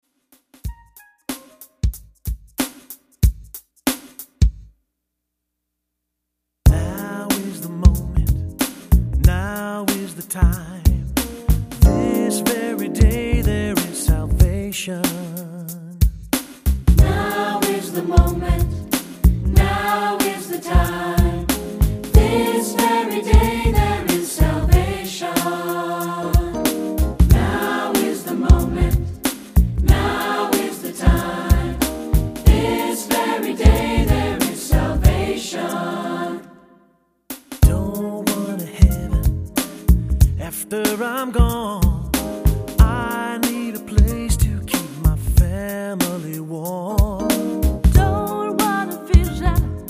Accompaniment:      Reduction, Percussion
Music Category:      Christian
Improvised Percussion.